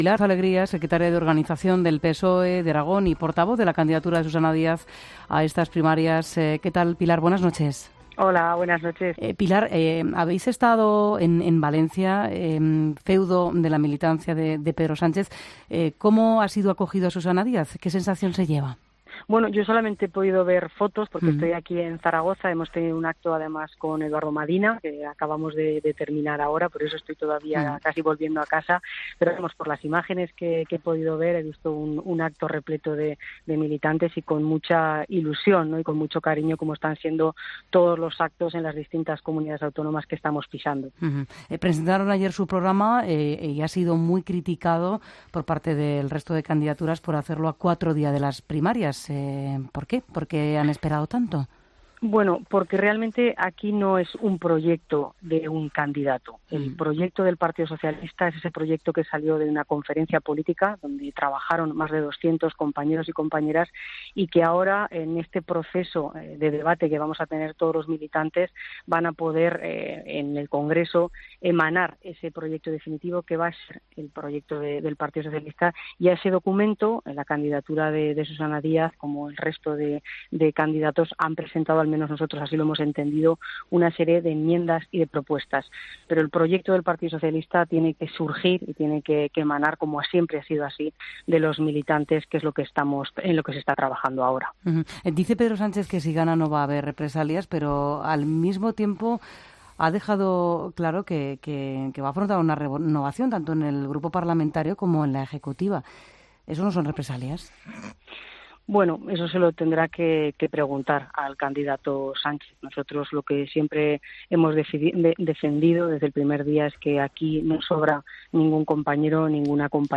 Entrevista a Pilar Alegría, portavoz de la candidatura de Susana Díaz